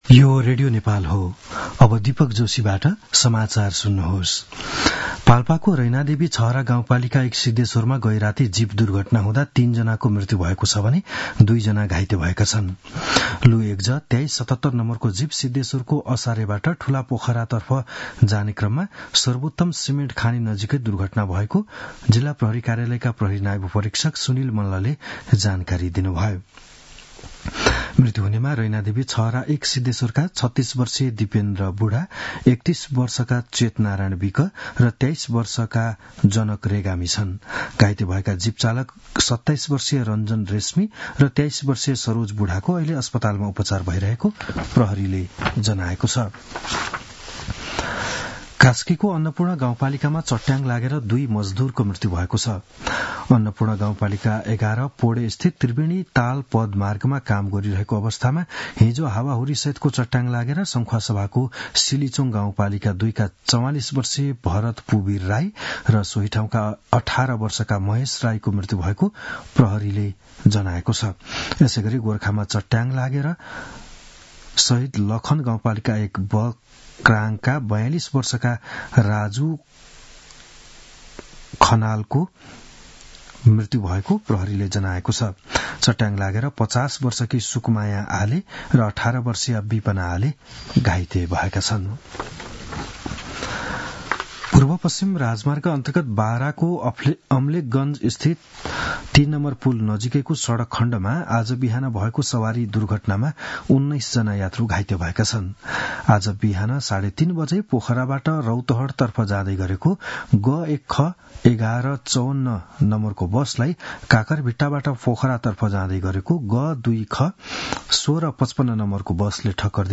बिहान ११ बजेको नेपाली समाचार : १५ वैशाख , २०८२
11-am-news-1-14.mp3